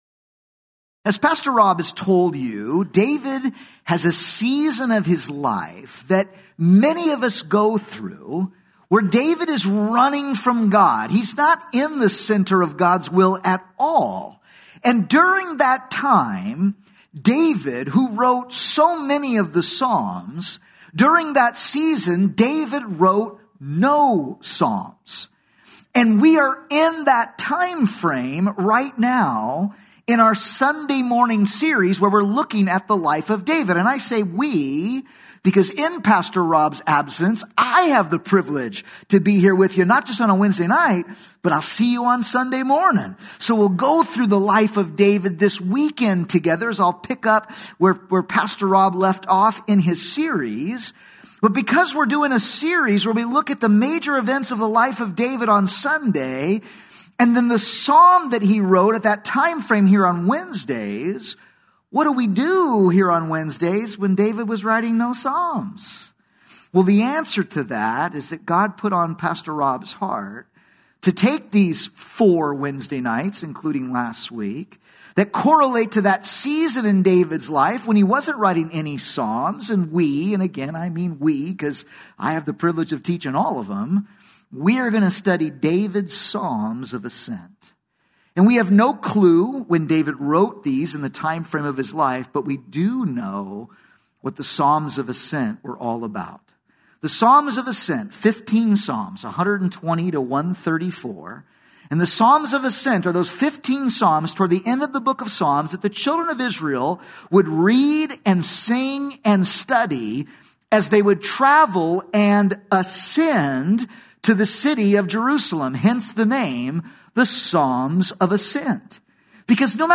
Wednesday Bible studies